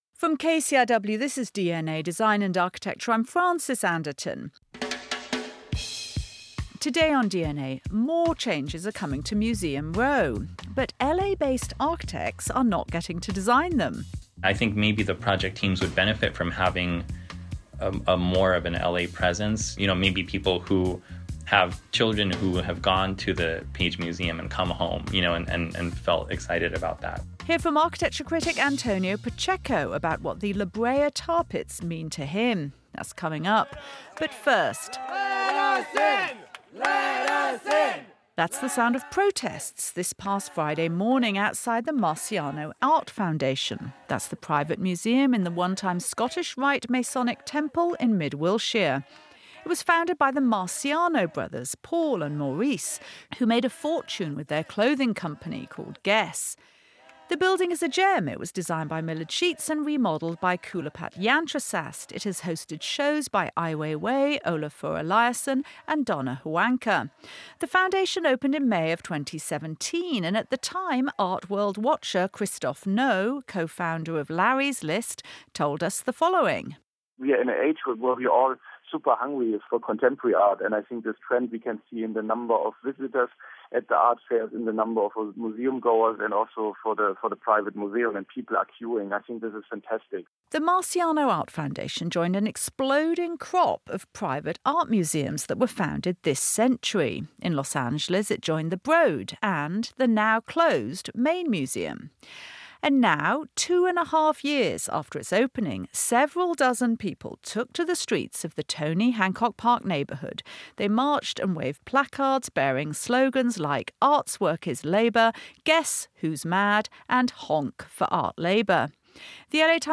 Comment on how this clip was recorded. DnA attends a protest outside the Wilshire museum to hear about the role of low-paid labor in the highly lucrative world of contemporary art.